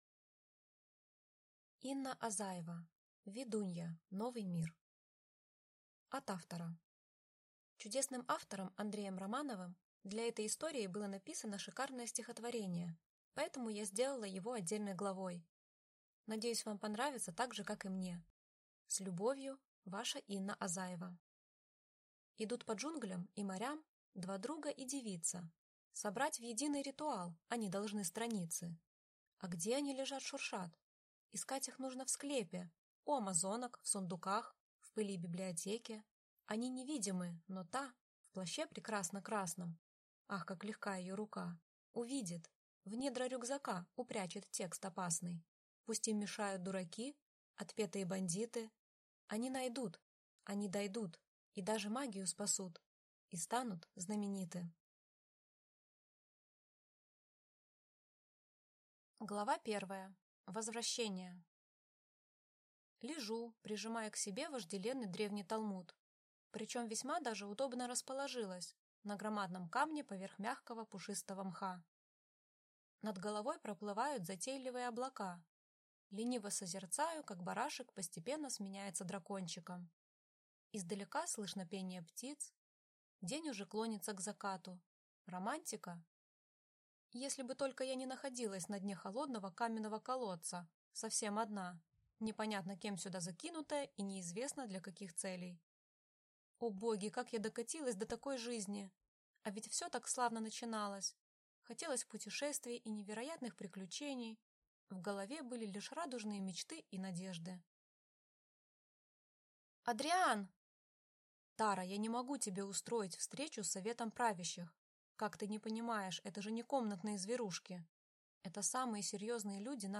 Аудиокнига Ведунья. Новый мир | Библиотека аудиокниг